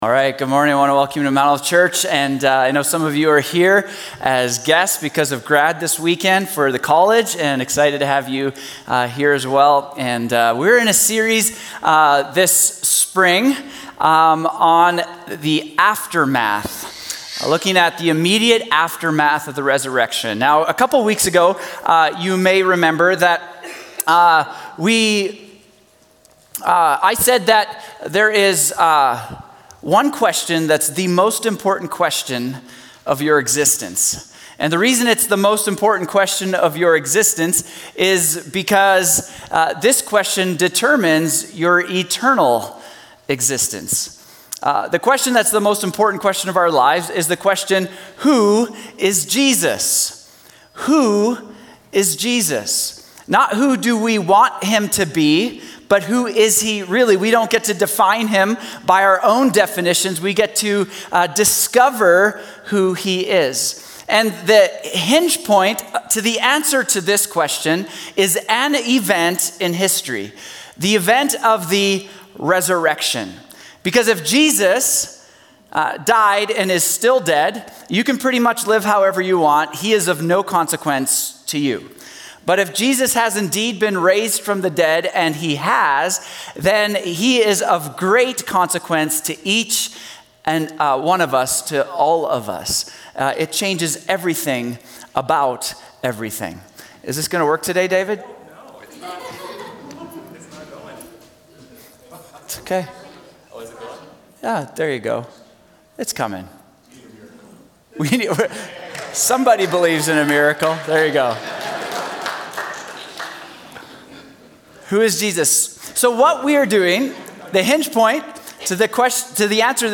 Sermons | Mount Olive Church